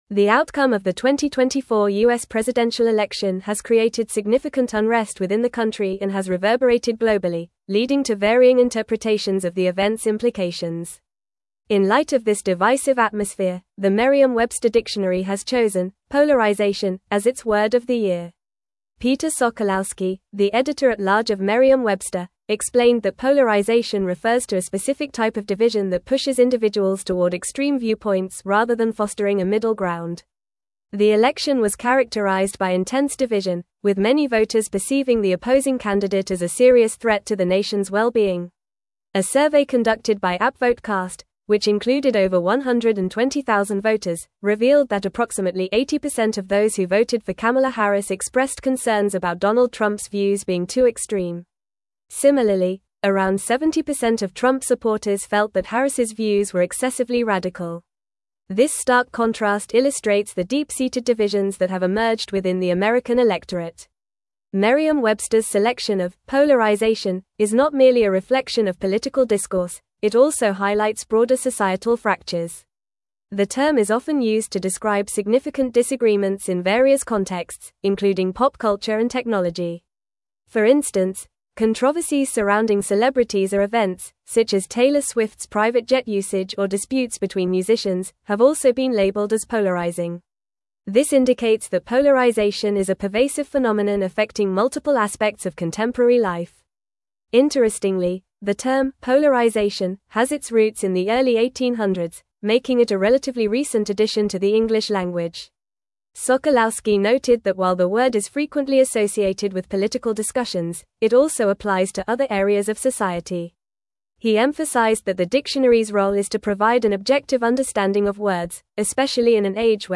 Fast
English-Newsroom-Advanced-FAST-Reading-Polarization-Named-Merriam-Websters-Word-of-the-Year.mp3